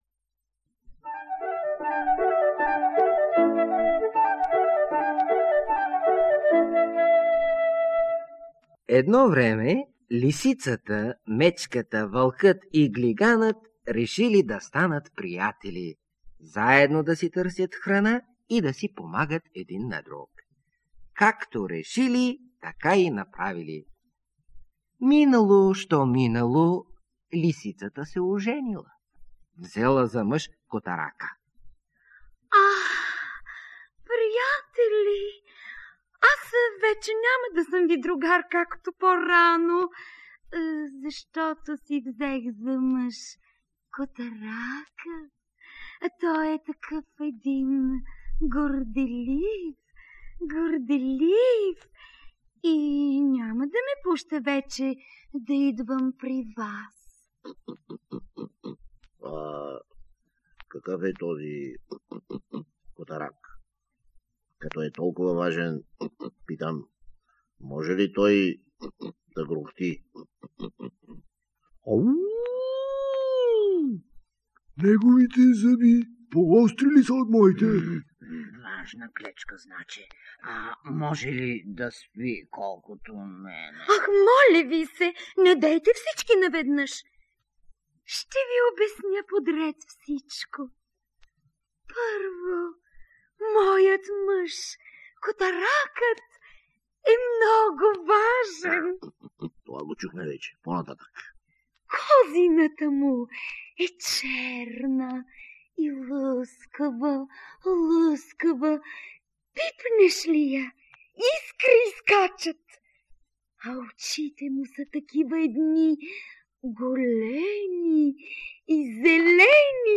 Котаракът_и_лисицата--м.плоча.mp3